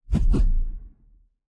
somersalt_10.wav